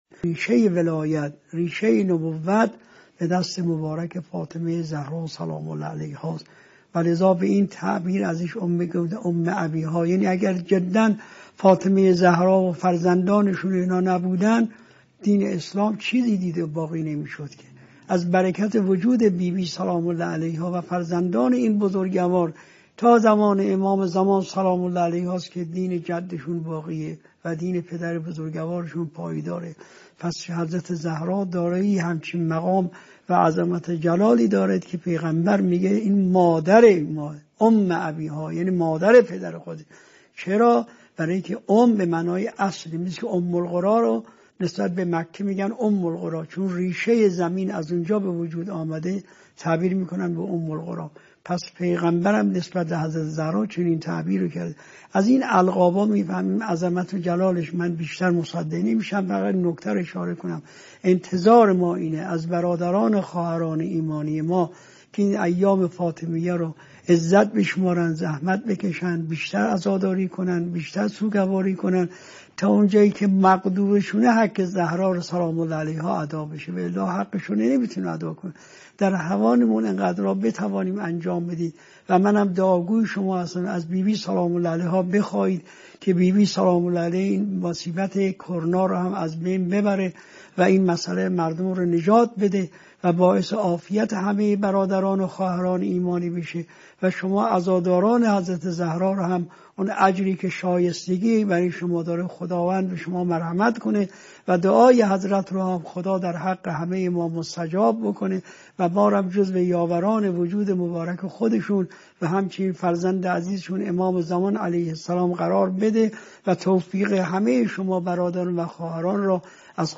به گزارش خبرگزاری حوزه، مرحوم آیت الله علوی گرگانی در یکی از سخنرانی های خود به مناسبت ایام فاطمیه به موضوع «حضرت زهرا سرچشمه‌ی به عنوان ولایت و نبوت » اشاره کرده‌اند که تقدیم شما فرهیختگان می شود.